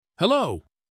anouncer_intro_01.ogg